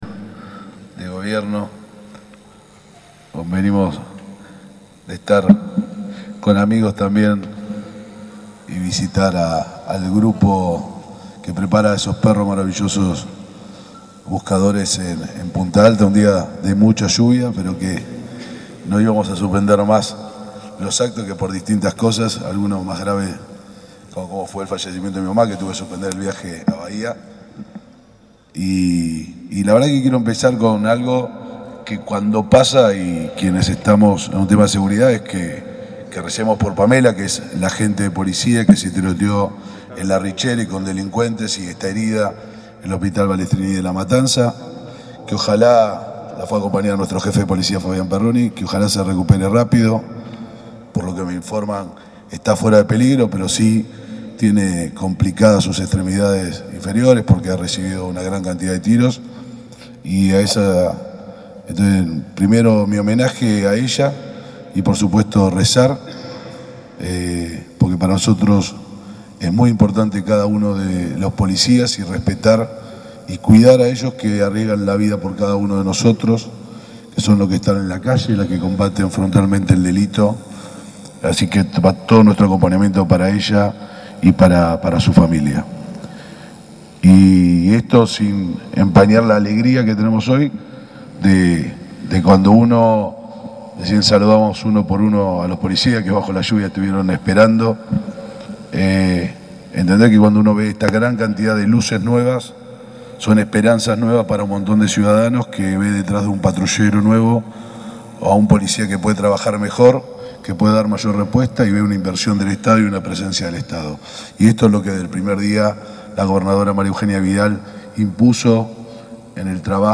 El ministro de Seguridad de la provincia de Buenos Aires, Cristian Ritondo y el intendente Héctor Gay, encabezaron la presentación formal de 31 automóviles, 10 camionetas y 20 motos que se destinarán a las distintas dependencias policiales de Bahía Blanca.